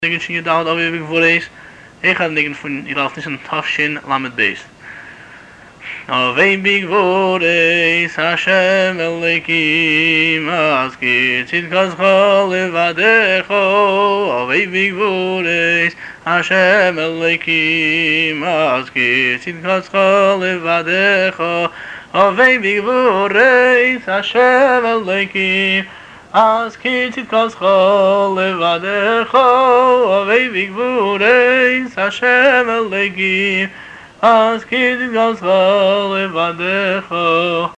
הניגון אבוא בגבורות הינו ניגון שמח ועליז שהותאם למילים מפרקו האישי החדש של הרבי, ביום הולדתו השבעים (תשל"ב):